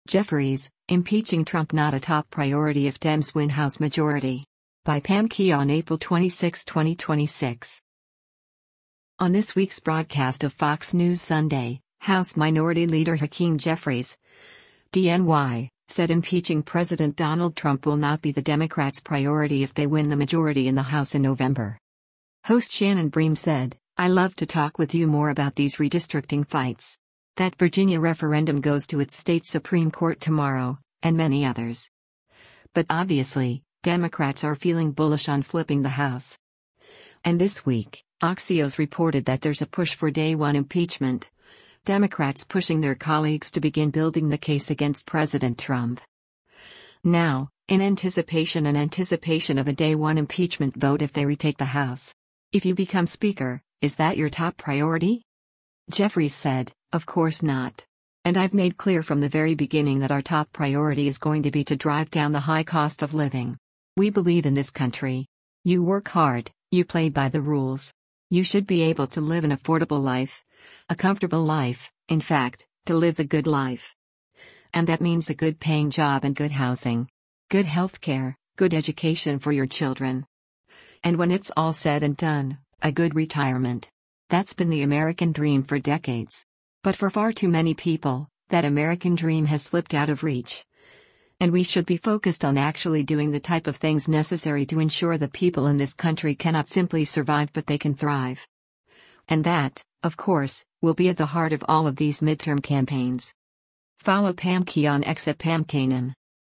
On this week’s broadcast of “Fox News Sunday,” House Minority Leader Hakeem Jeffries (D-NY) said impeaching President Donald Trump will not be the Democrats’ priority if they win the majority in the House in November.